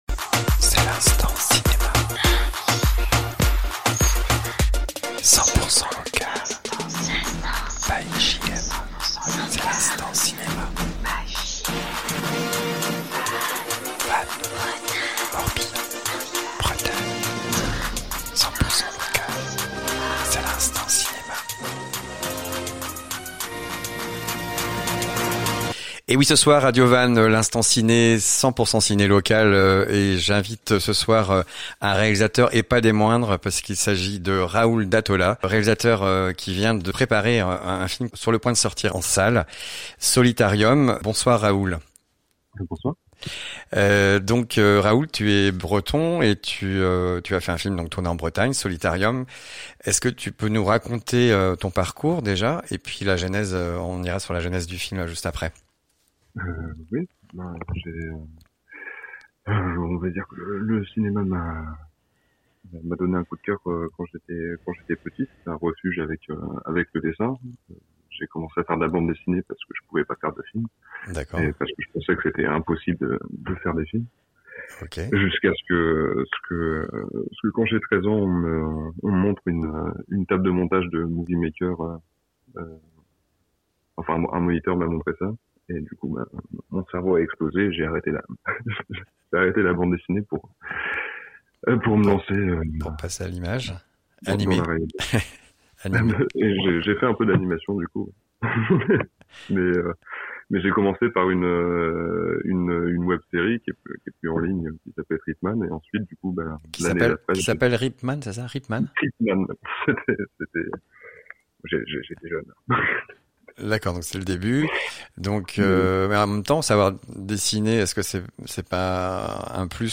j'interviewe